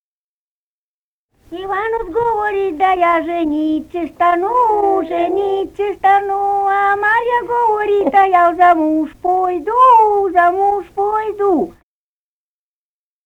(свадебная).